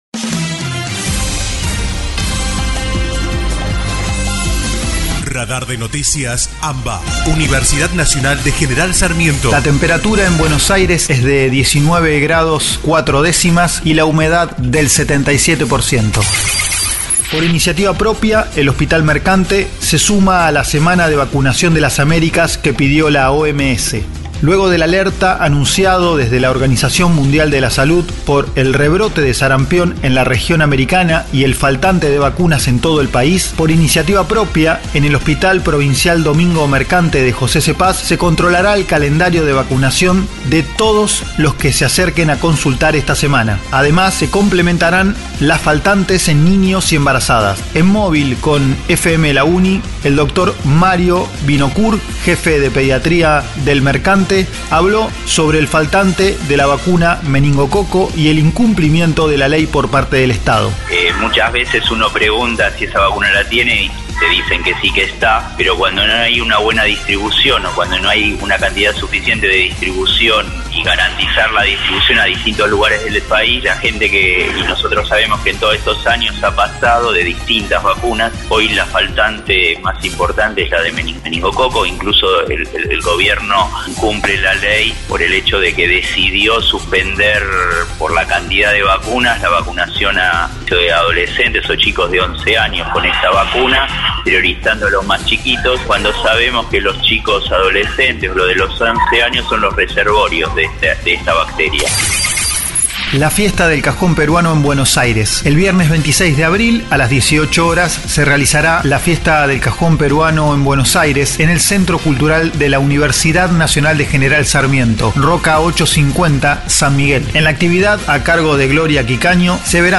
Panorama informativo «Radar de Noticias AMBA» , realizado de manera colaborativa entre las emisoras de las Universidades Nacionales de La Plata, Luján, Lanús, Arturo Jauretche, Avellaneda, Quilmes, La Matanza y General Sarmiento, integrantes de ARUNA (Asociación de Radiodifusoras Universitarias Nacionales Argentinas).